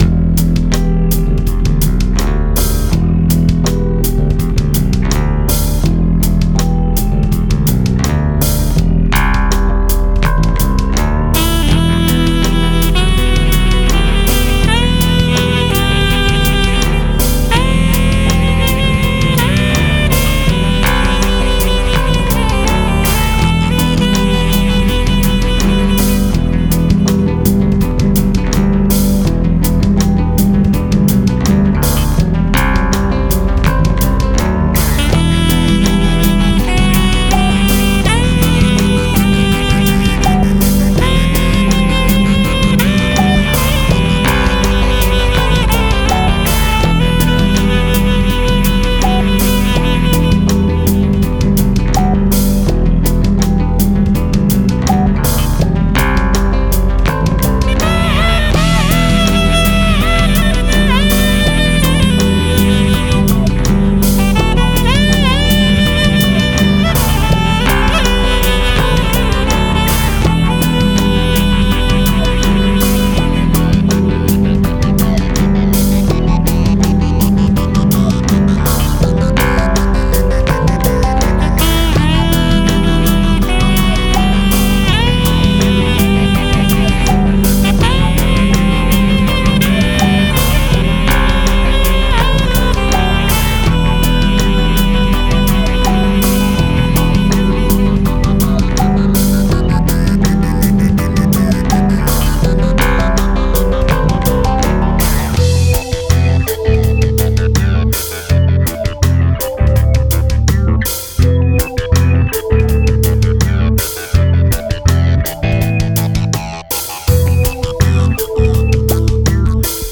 sax and flute
signature bass grooves